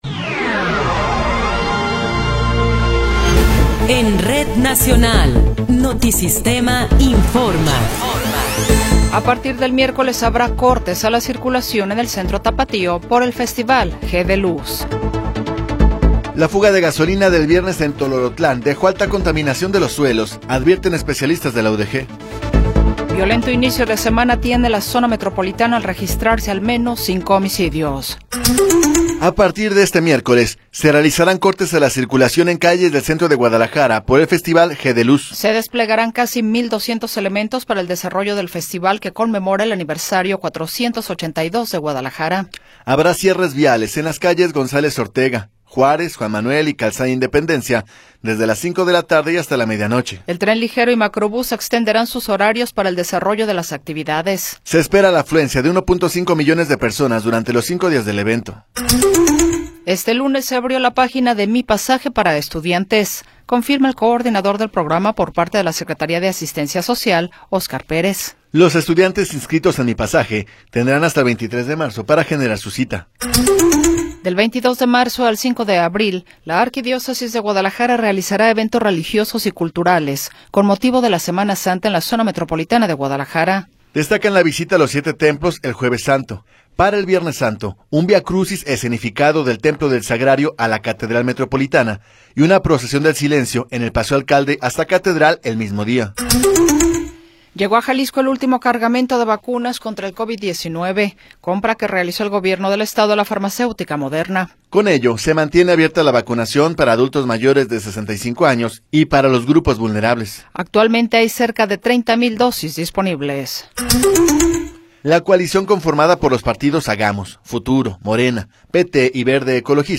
Noticiero 20 hrs. – 12 de Febrero de 2024
Resumen informativo Notisistema, la mejor y más completa información cada hora en la hora.